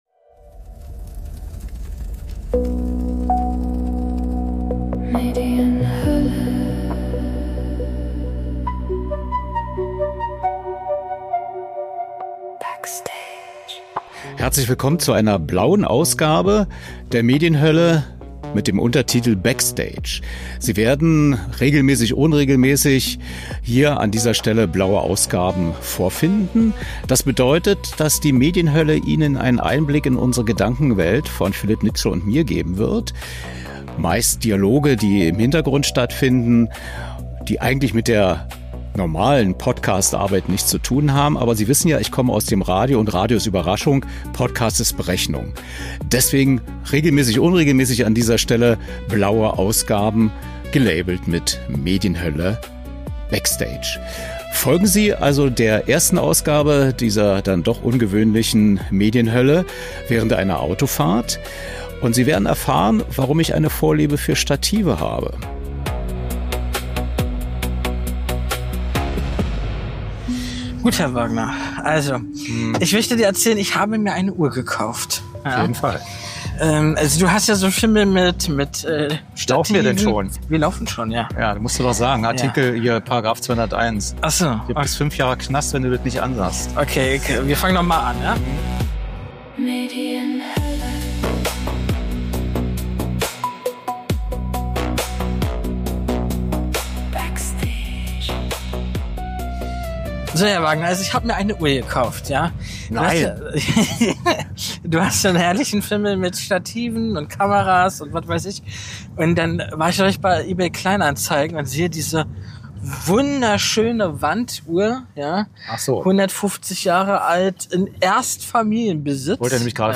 Beschreibung vor 4 Tagen In der "blauen Medienhölle" wird hier regelmäßig unregelmäßig das Mikrofon bei Backstage-Gesprächen dabei sein.